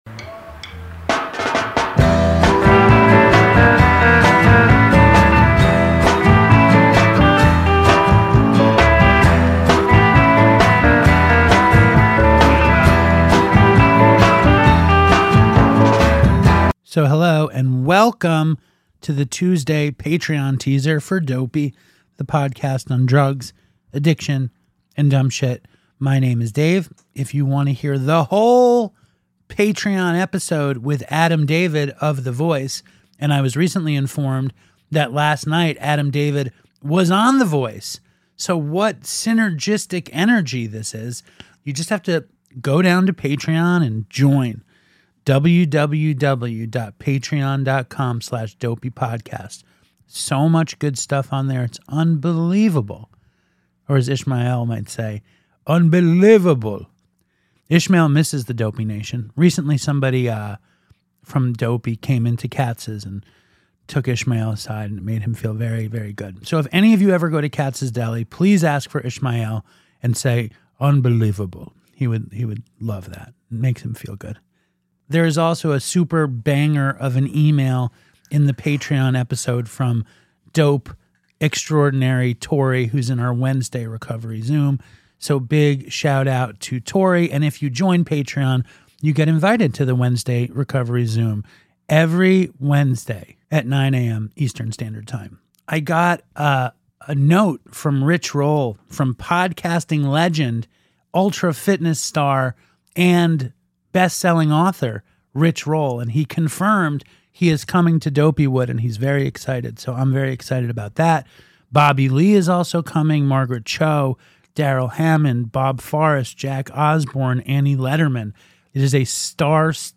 Comedy, Health & Fitness, Health & Fitness:mental Health, Mental Health, Alternative Health